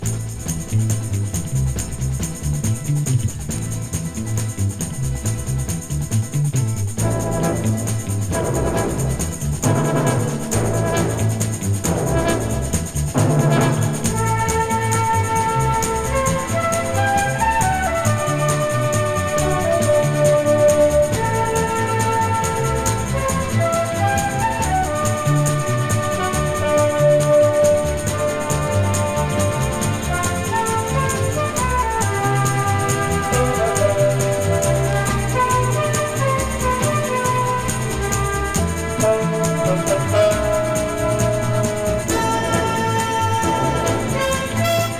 軽やかで流麗な演奏が最高で、素敵な瞬間がただただ溢れ出す好盤です。
Jazz, Pop, Easy Listening　USA　12inchレコード　33rpm　Stereo